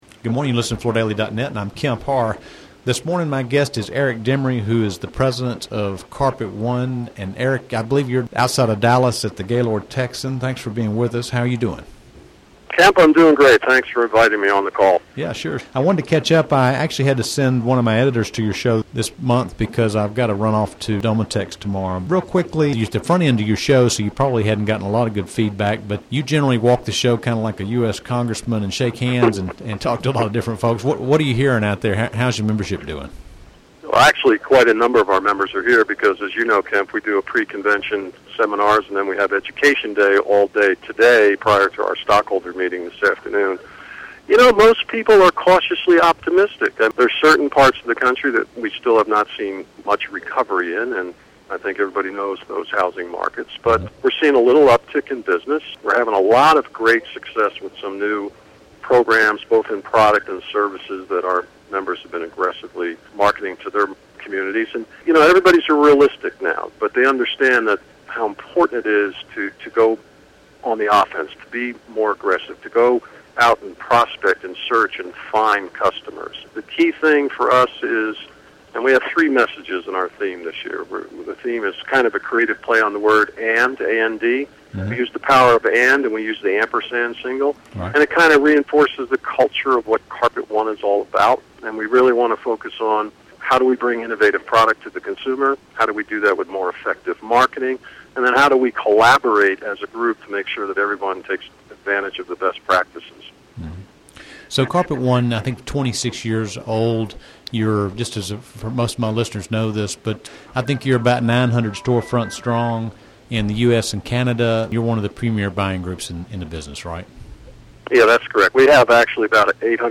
Listen to the interview to hear details on how the group's membership is doing, information on a few of the key speakers, and some new product initiatives for 2011.